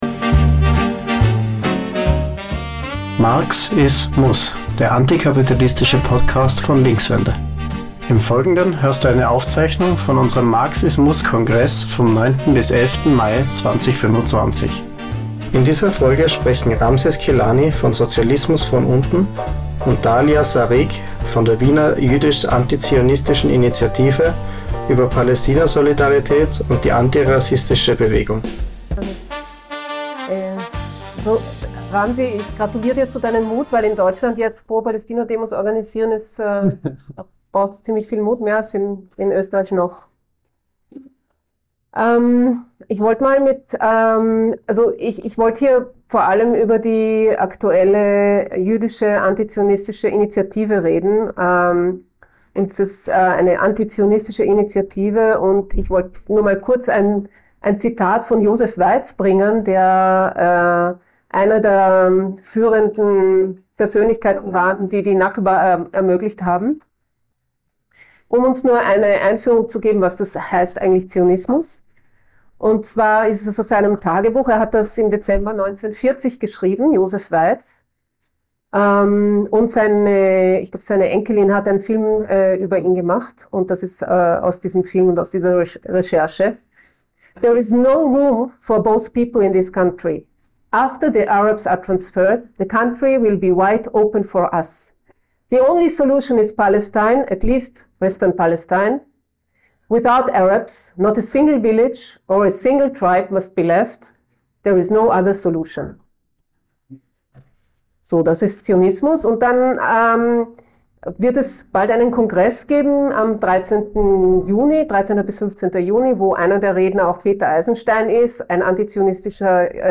Du hörst eine Aufzeichnung vom MARX IS MUSS Kongress von 09.-11. Mai 2025 in Wien.